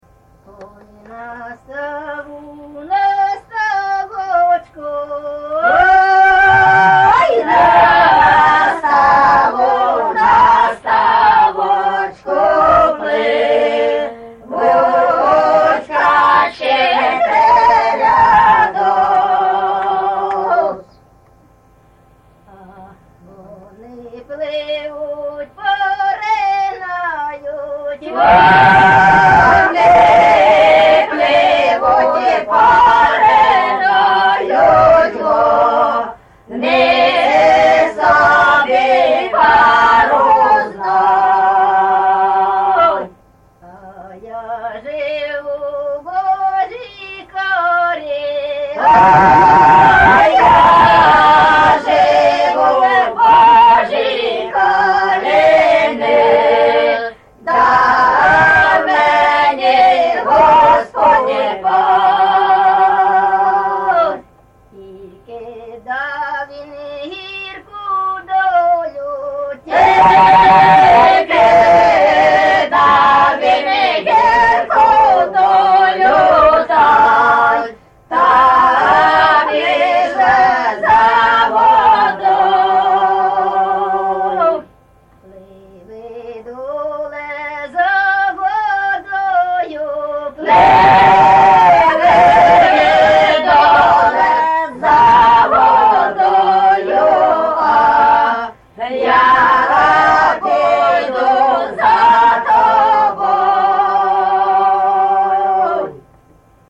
ЖанрПісні з особистого та родинного життя
МотивНещаслива доля, Журба, туга
Місце записус. Лука, Лохвицький (Миргородський) район, Полтавська обл., Україна, Полтавщина